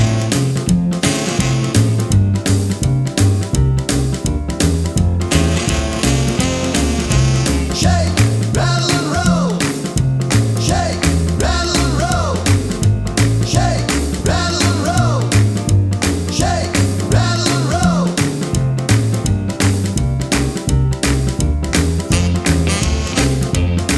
No Saxophone Rock 'n' Roll 2:31 Buy £1.50